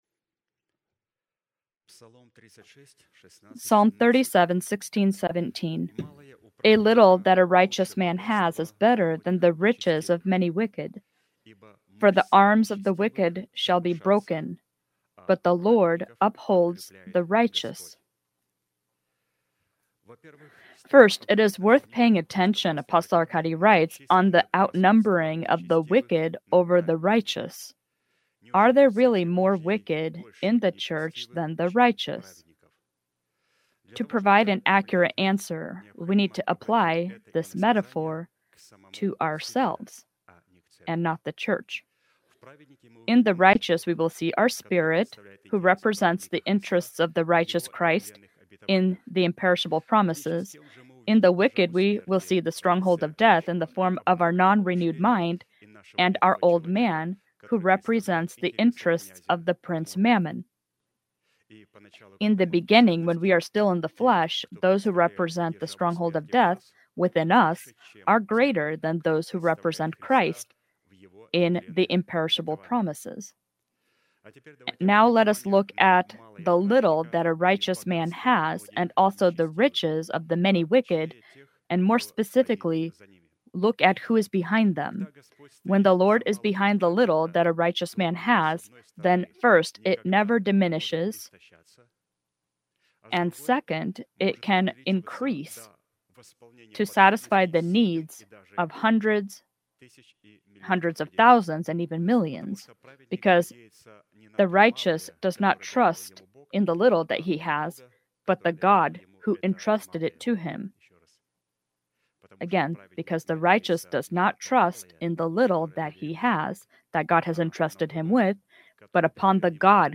Проповедник
Sermon title: Return to the Ancient Path of Goodness